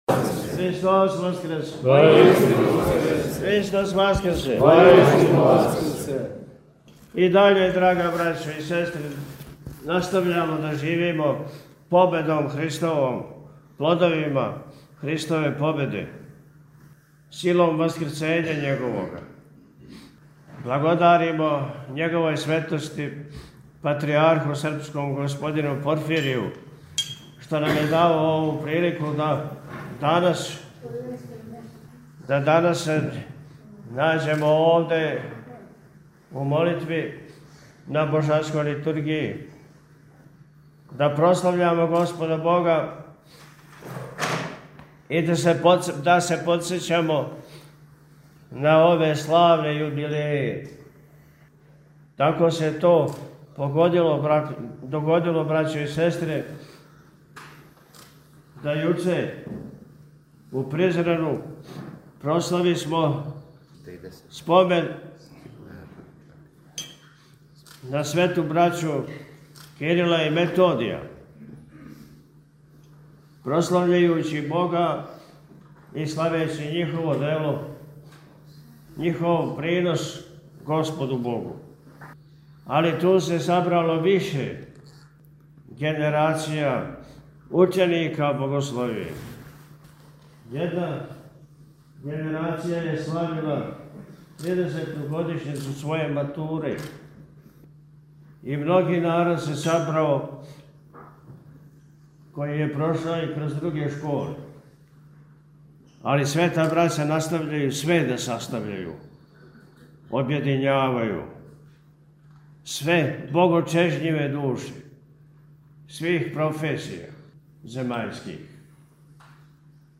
По благослову Патријарха српског г. Порфирија Његово Високопреосвештенство Архиепископ и Митрополит милешевски г. Атанасије служио је 25. маја 2025. године, у Недељу шесту по Васкрсу и на дан молитвеног помена Светог Никодима Архиепископа Пећког, Свету архијерејску Литургију у храму Светих Апостола у Пећкој Патријаршији.
Честитајући празник свима сабранима Високопреосвећени је у пастирској беседи рекао: – И даље настављамо да живимо Победом Христовом, плодовима Христове победе, силом Васкрсења Његовога.